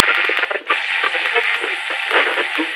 radio_mixdown_1.ogg